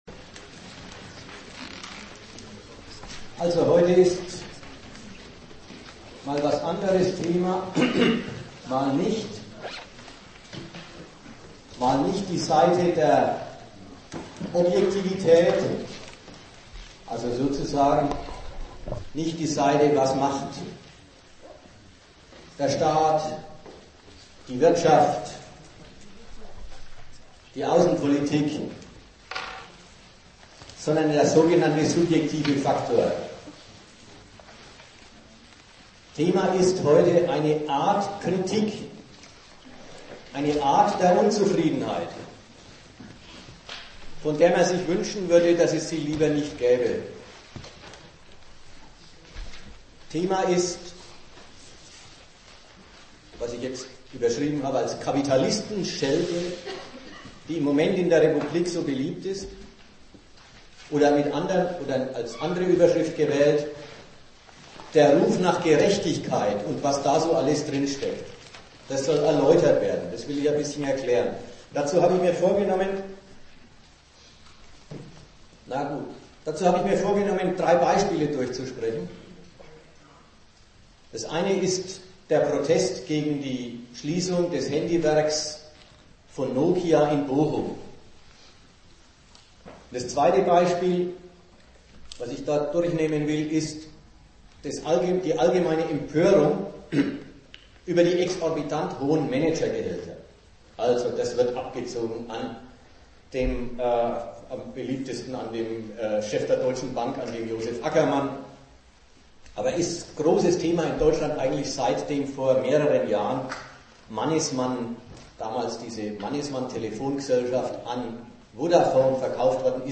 Nürnberg
Der Vortrag wird den hohen Wert "Gerechtigkeit" erläutern und die nationale Sehnsucht danach.
Teil 6: Diskussion